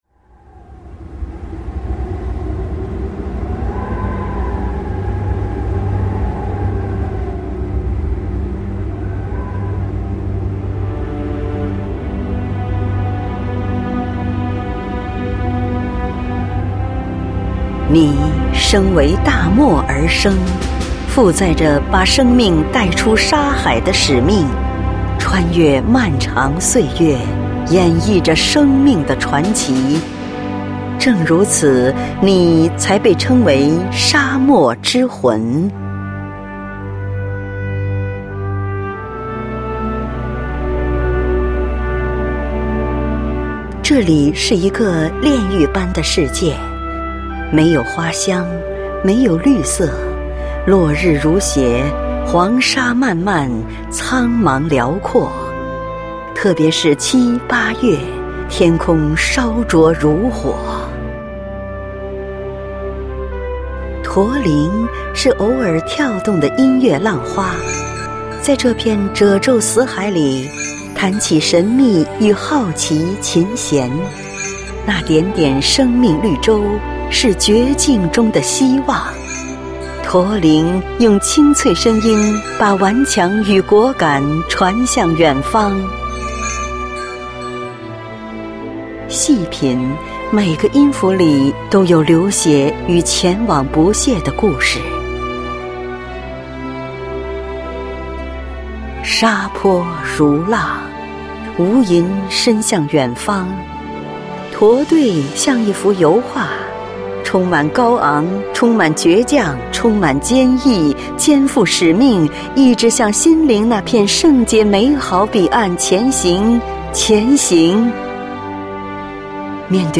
配樂散文詩朗誦（音頻）：沙漠之魂（MP3）